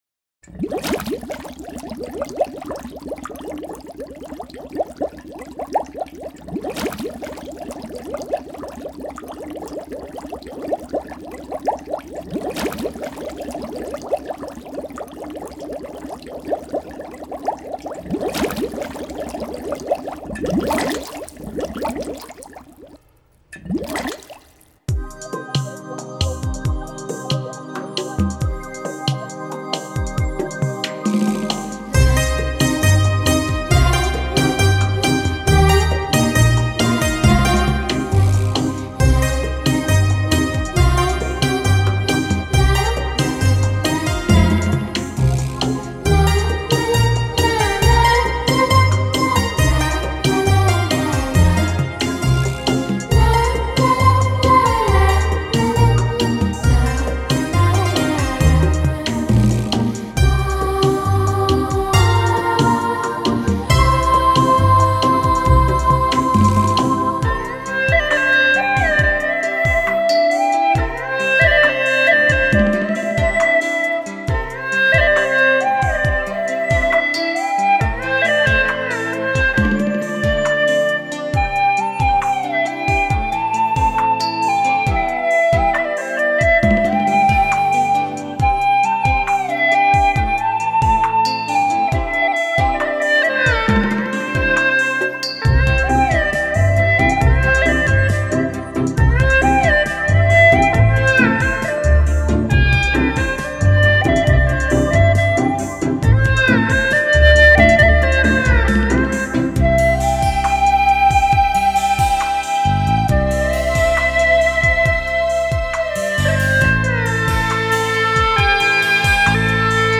3周前 纯音乐 5